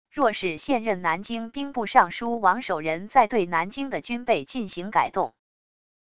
若是现任南京兵部尚书王守仁在对南京的军备进行改动wav音频生成系统WAV Audio Player